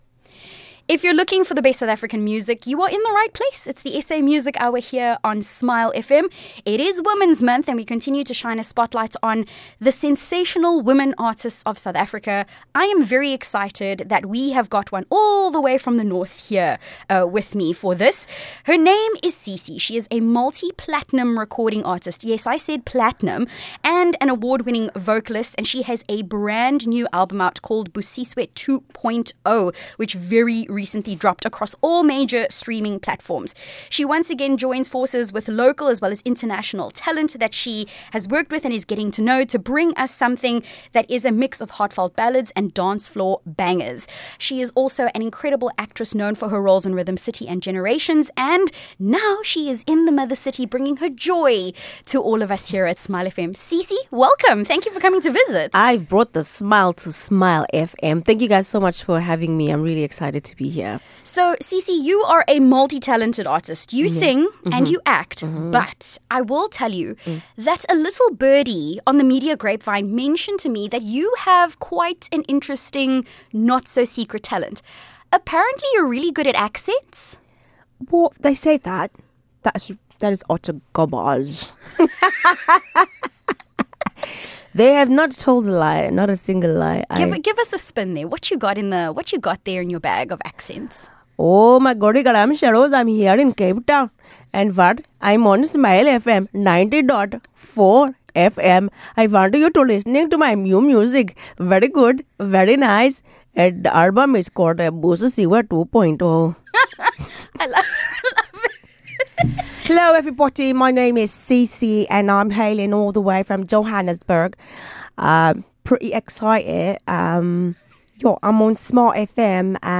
Multi-platinum recording artist and award-winning vocalist Cici celebrated her album, Busisiwe 2.0, by visiting radio station all across South Africa, including Smile FM. This vibrant and dynamic music artist and actress brought her bubbly energy as she chatted about the album and showed off her sense of humour on the SA Music Hour.